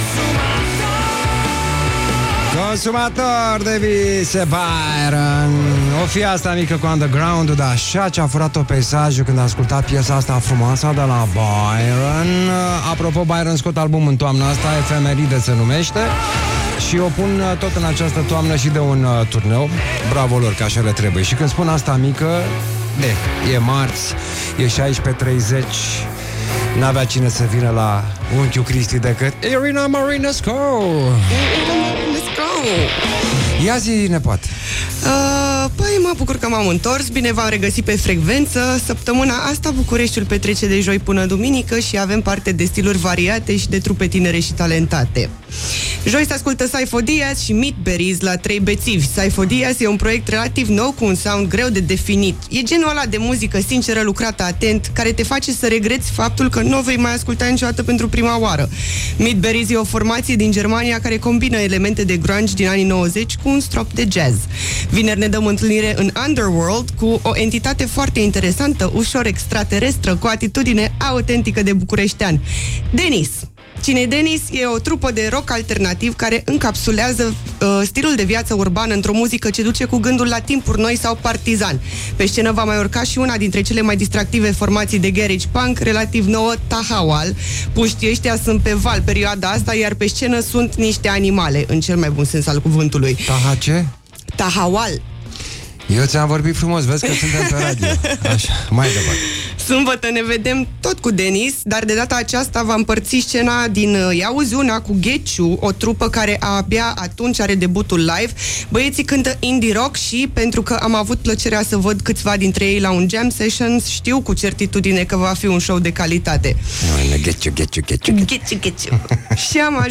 Cum a bătut ceasul ora 16.30, cum am bătut și eu la ușa studioului Rock FM